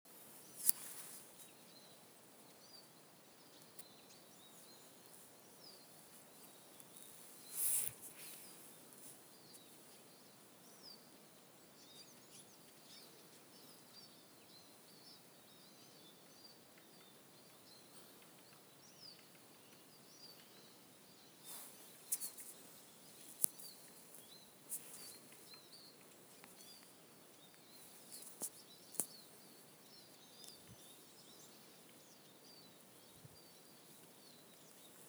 Birds -> Finches ->
Siskin, Spinus spinus
StatusSinging male in breeding season